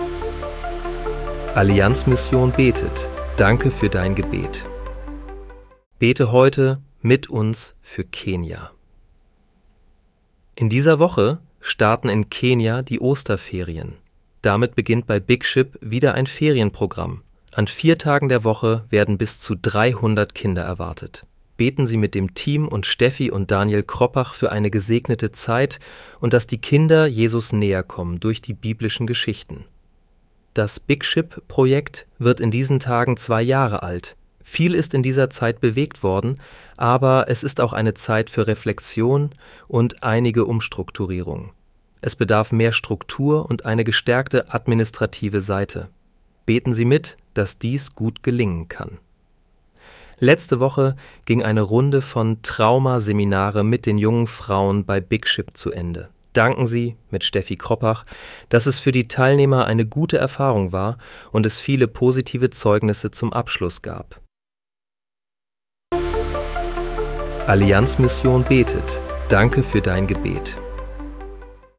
(KI-generiert mit der